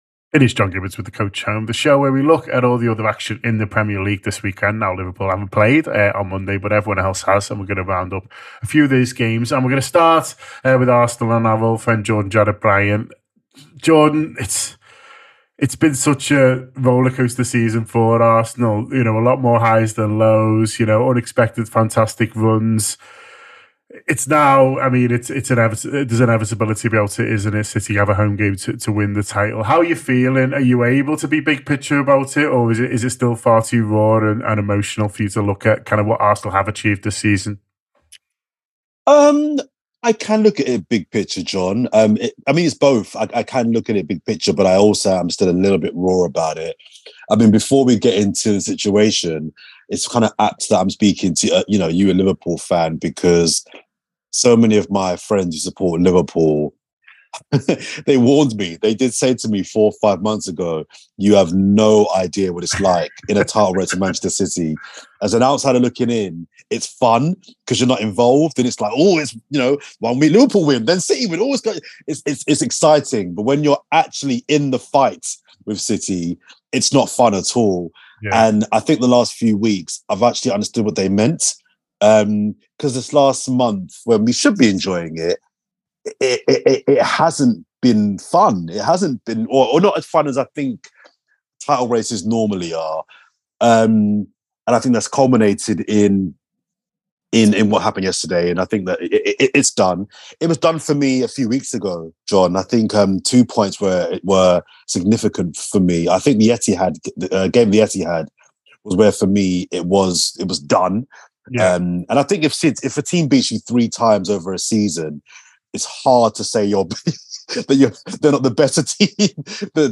Fan reaction to the weekend’s Premier League games, after Arsenal were beaten by Brighton & Hove Albion to all but end their title hopes.
Below is a clip from the show – subscribe for more on the other Premier League teams…